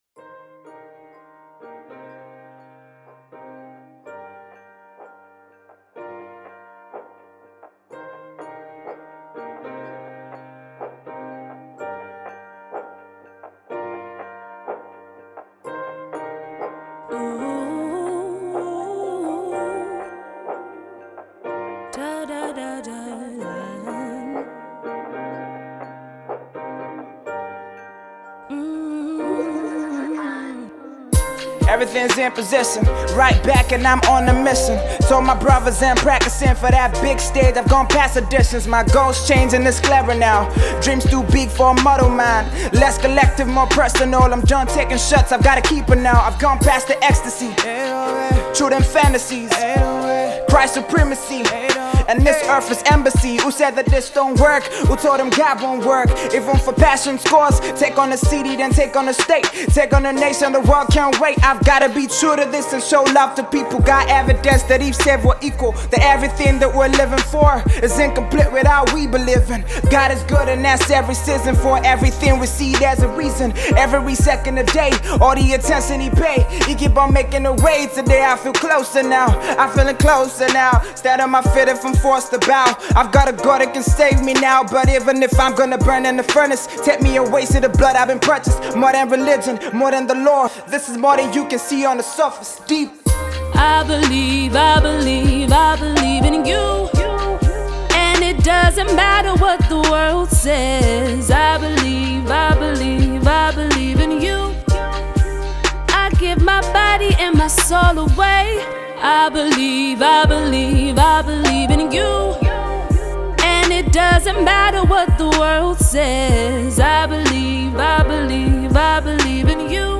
Genre: Christian Rap / Hip Hop